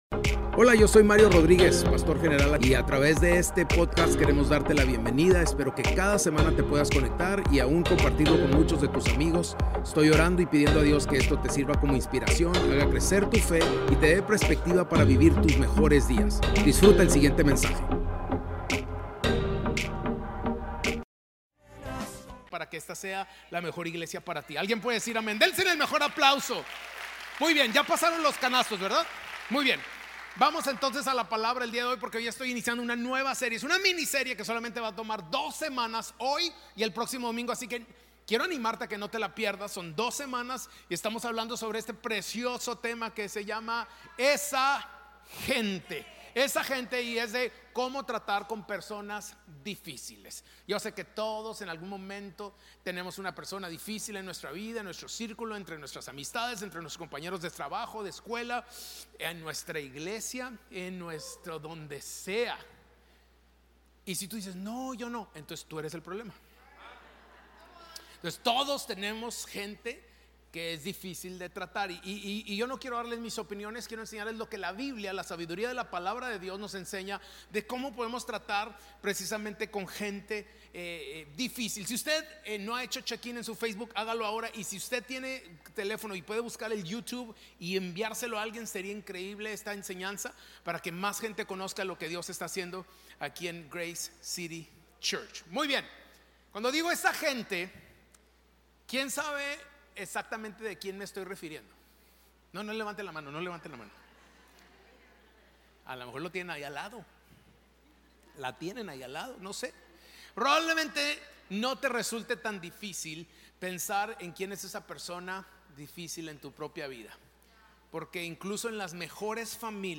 Spanish Sermons